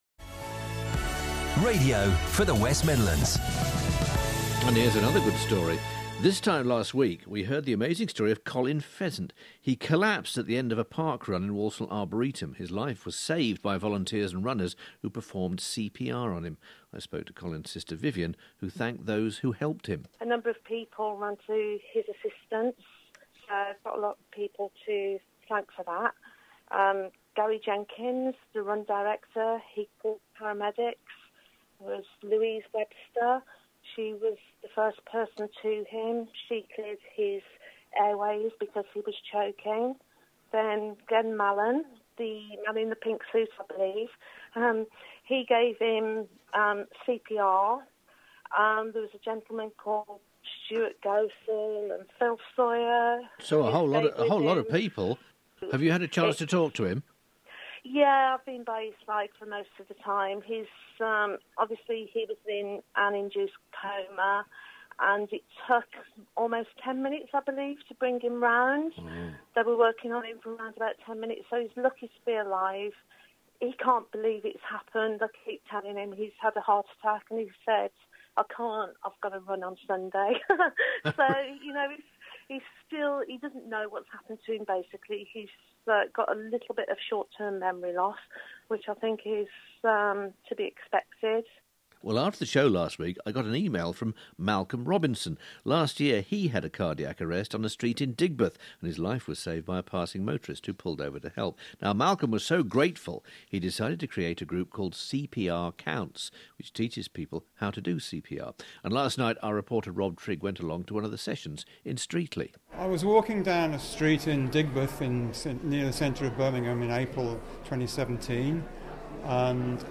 On 16th October 2018, World Restart A Heart Day, we had a reporter from BBC Radio WM at our course at Streetly Sports & Community Association.